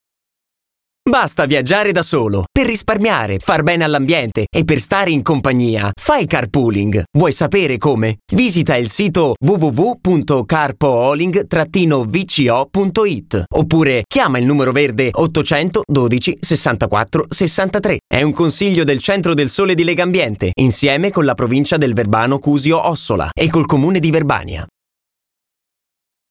download di car-pooling - spot vocale